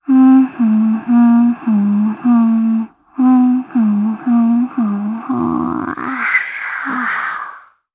Gremory-Vox_Hum_jp.wav